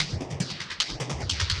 Index of /musicradar/rhythmic-inspiration-samples/150bpm